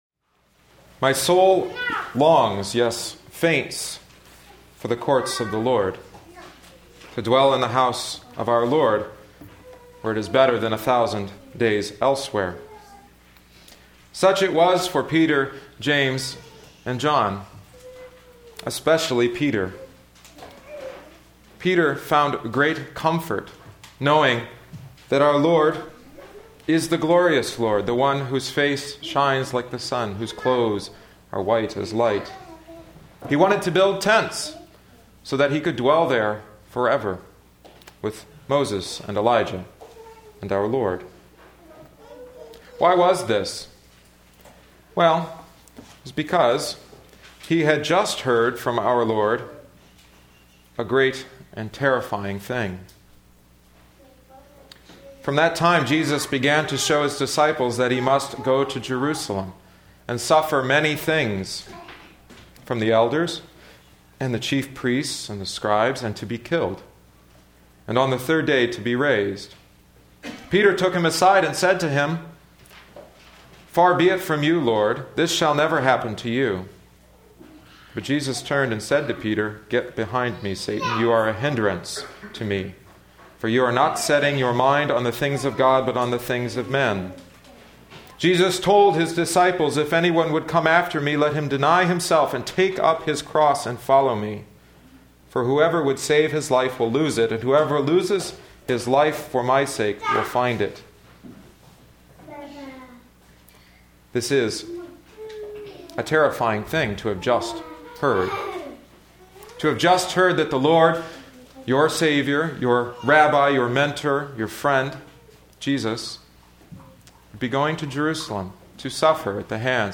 in Sermons |
MP3 Version: Divine Service 2012-01-29